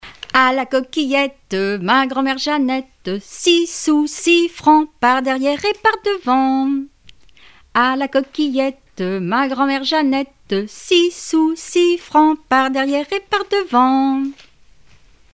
enfantine : comptine
Répertoire de chansons populaires et traditionnelles
Pièce musicale inédite